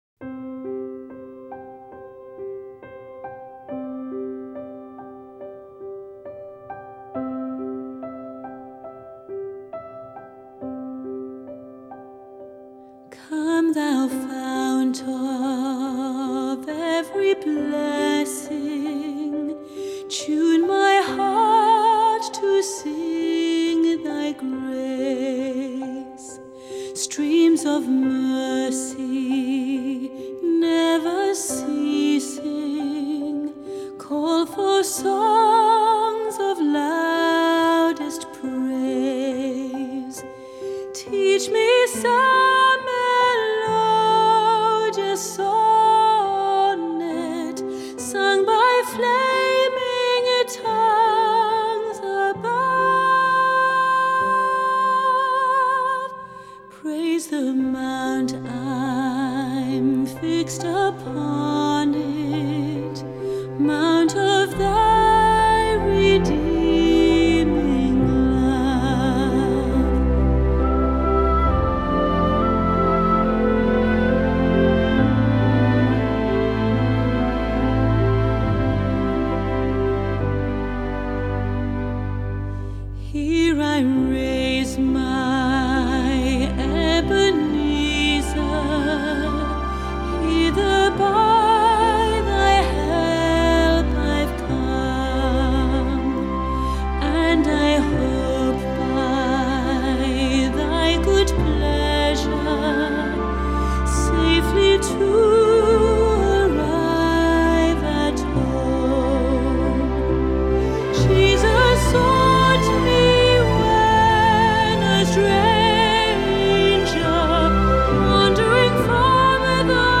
Crossover